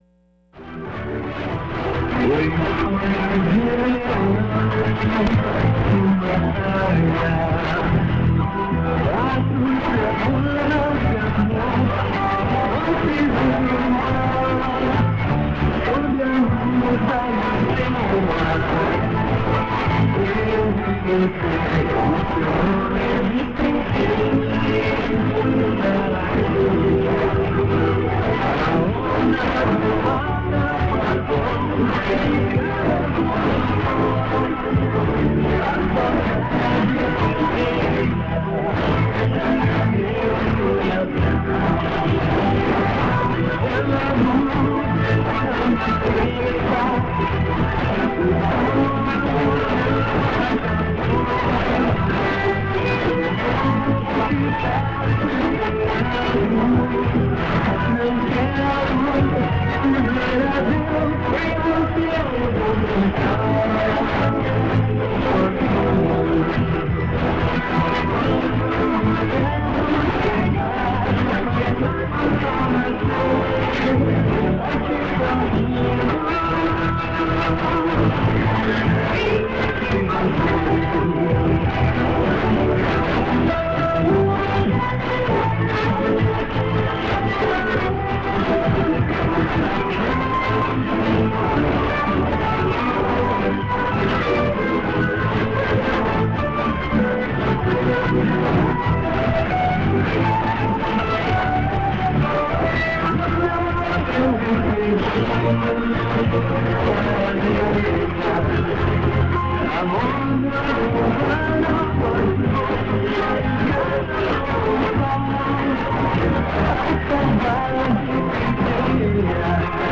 Good signal into EM80. 49+40.
0135 UTC - music